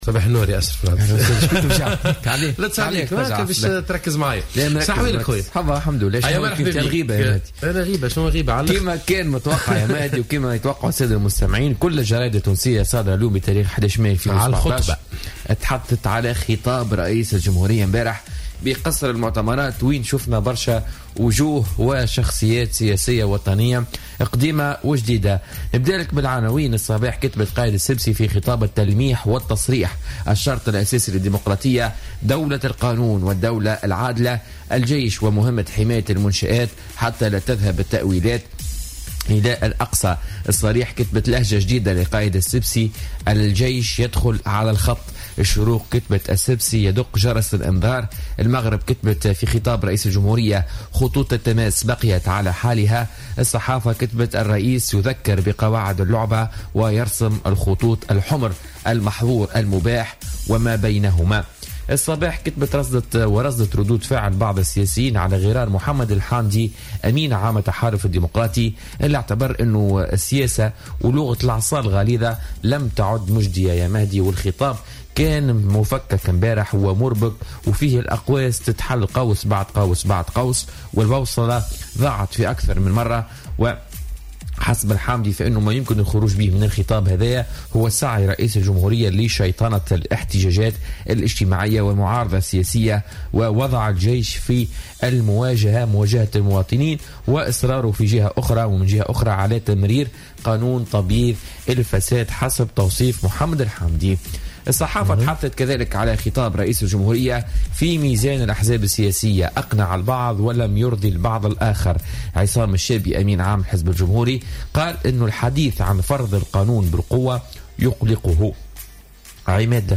Revue de presse du jeudi 11 mai 2017